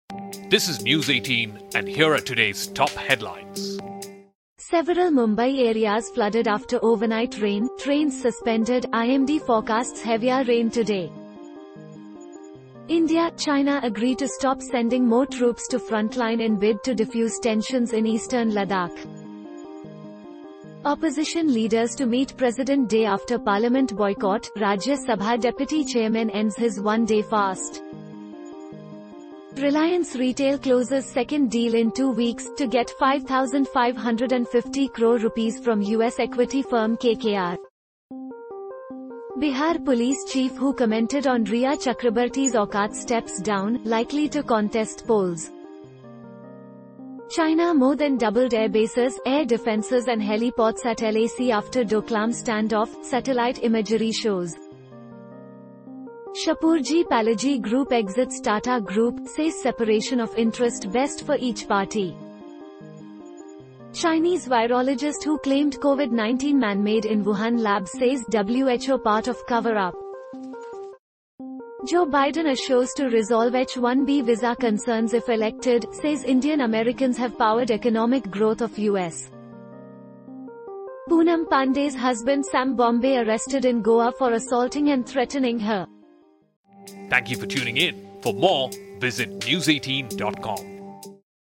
Catch up with the top headlines of the day with our Audio Bulletin, your daily news fix in under 2 minutes.